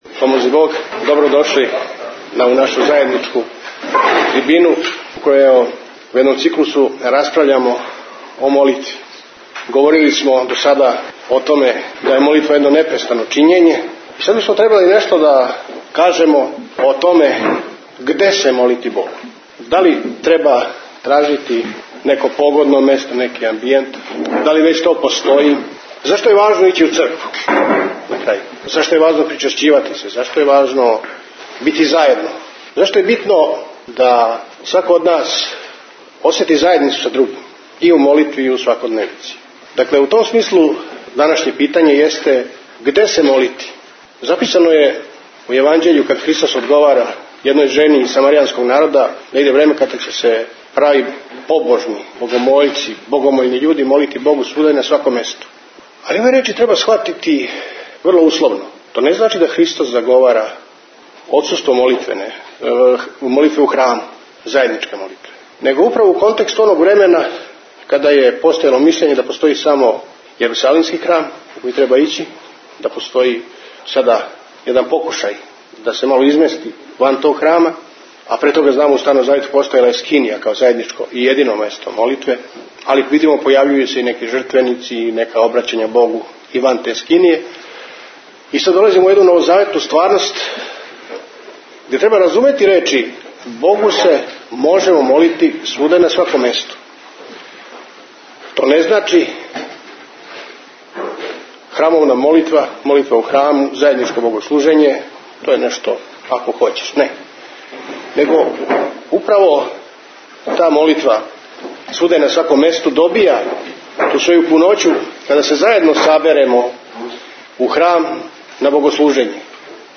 Гдје се молити Богу Tagged: Предавања
У присуству великог броја вјерника предавање је одржано у Капели Свете Петке на Калемегдану у оквиру редовних духовних разговора петком.